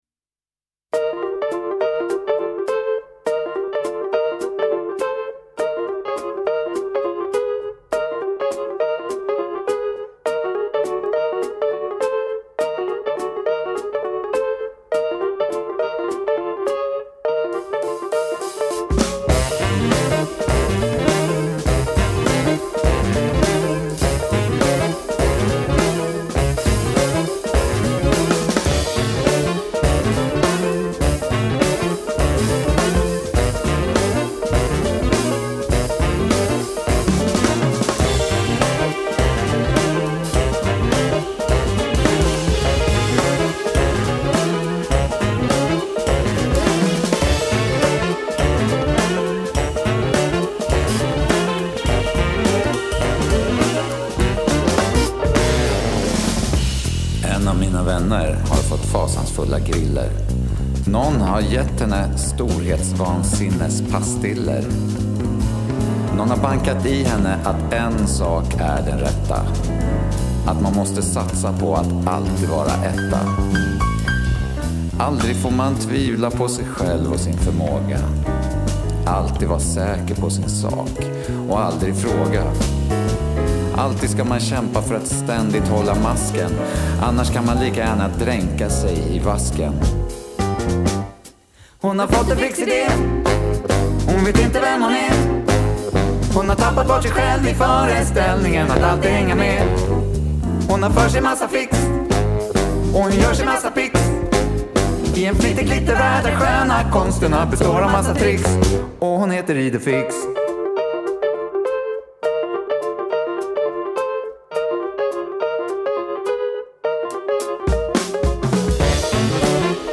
Som all musik på denna höga nivå låter den skenbart enkel.
Ystert, lekfullt, svängigt.